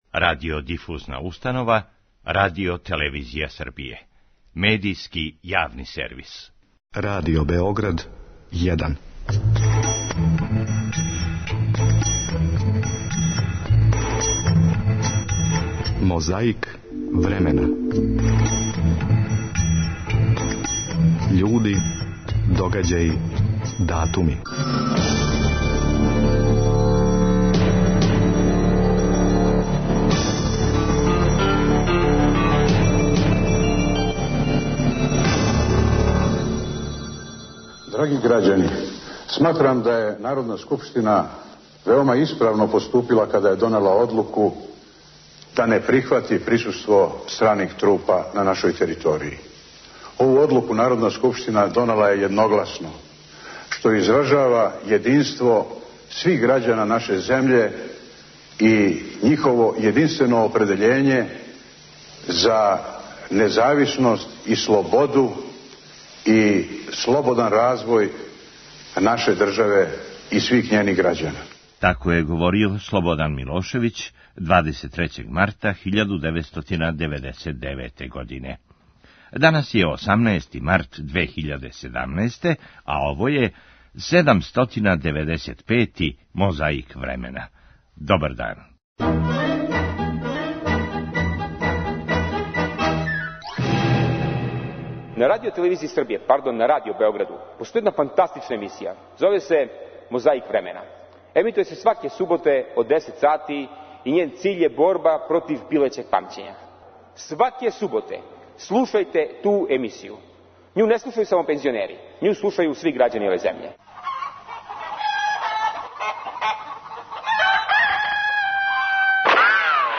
О разлозима због којих полиција није успела да спречи нереде говорио је министар унутрашњих послова Драган Јочић.
О још једном парадоксу у Срба говорио је филозоф и премијер Зоран Ђинђић на једном од путовања Србијом.